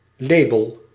Ääntäminen
Synonyymit etiket kaartje Ääntäminen Tuntematon aksentti: IPA: /ˈleːbəl/ Haettu sana löytyi näillä lähdekielillä: hollanti Käännös Ääninäyte Substantiivit 1. quality label 2. music label 3. label US Suku: n .